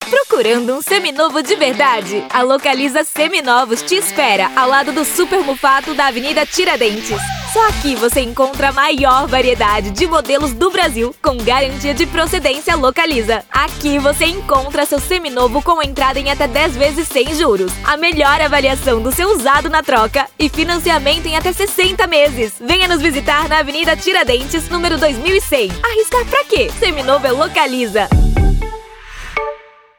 Sprechprobe: Werbung (Muttersprache):
I'm a Brazilian voice actress with a naturally youthful tone, perfect for portraying children (boys and girls), teens, and young adults. I specialize in expressive, colorful character voices — from playful and animated to sweet and sincere.